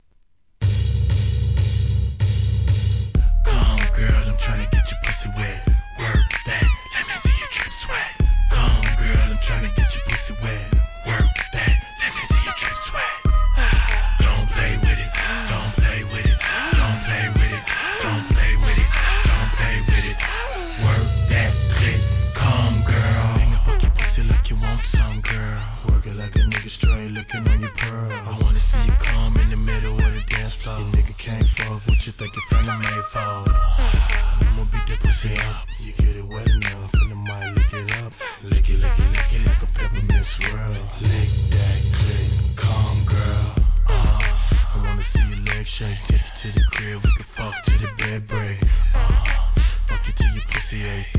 HIP HOP/R&B
2005年、当時流行のウィスパー・ヴォイスでのRAP!!